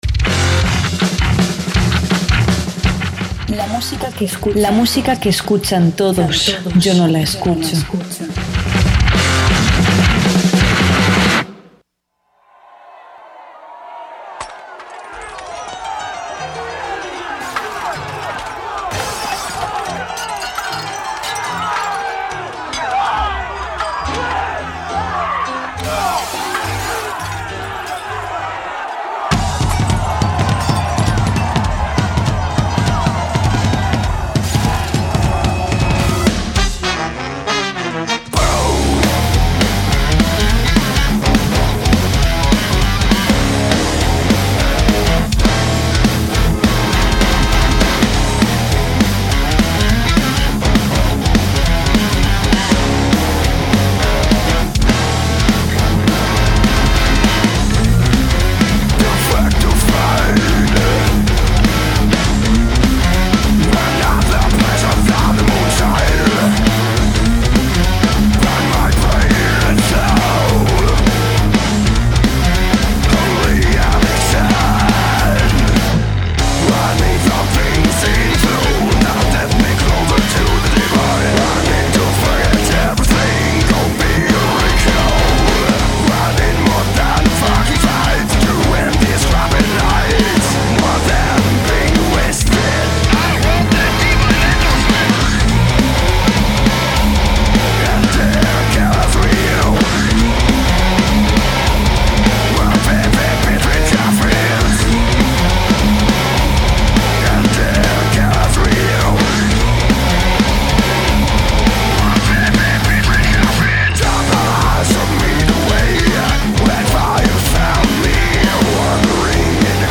banda de metal progresivo inglesa